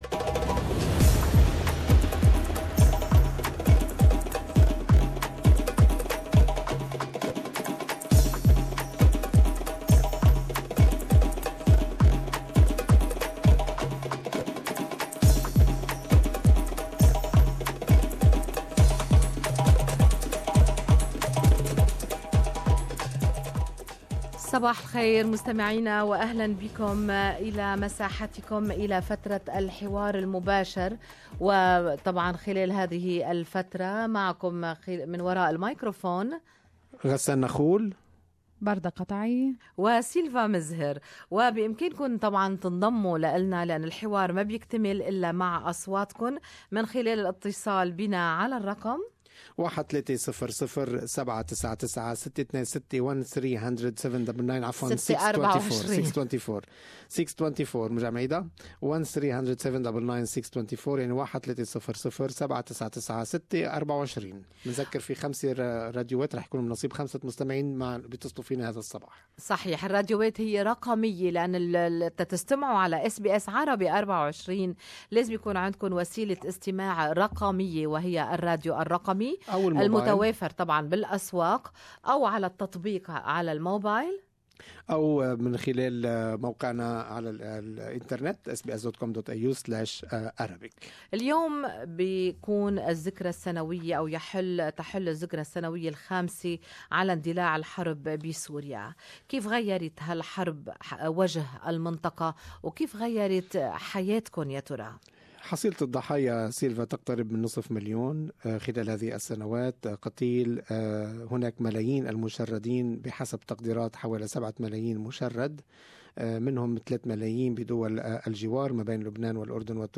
للأردن الحبيب ...لفلسطين قرّة العين...ولباقي الدول العربية ؟؟؟؟رسائل المستمعين في الحوار المباشر لأوطانهم